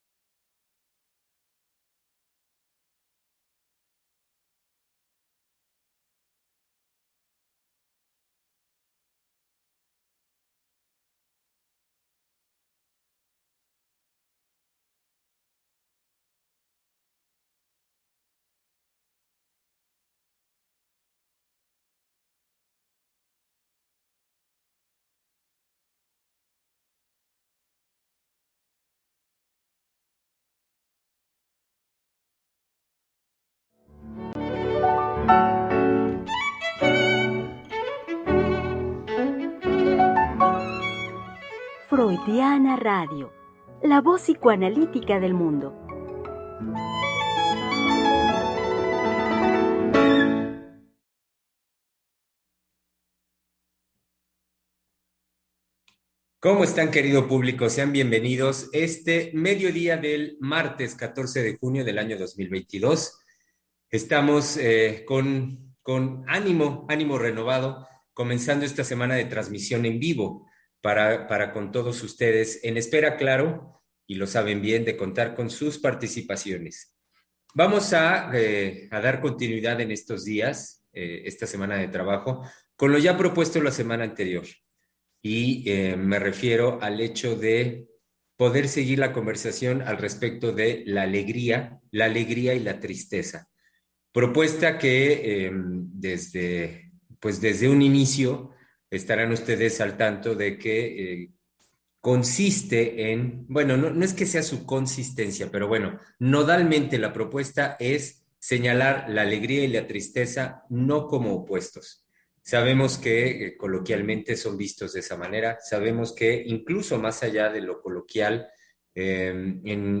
Programa transmitido el 14 de junio del 2022.